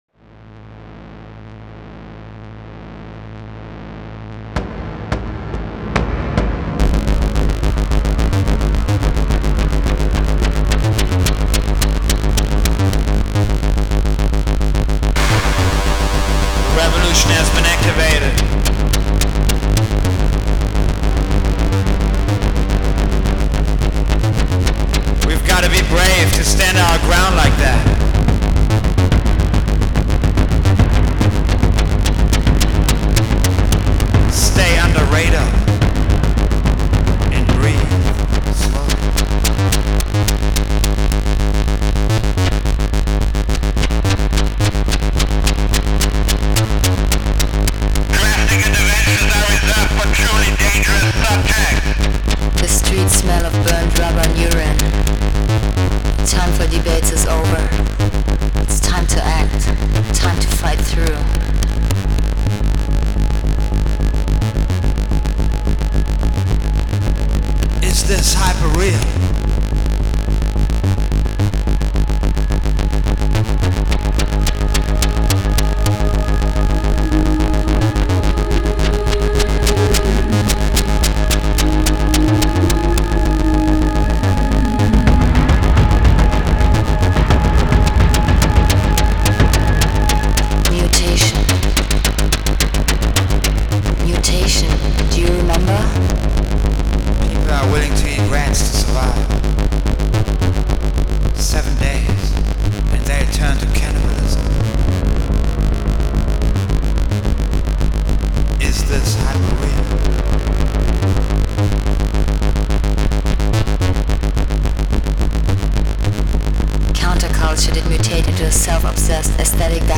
The music is relentless